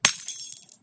default_break_glass.2.ogg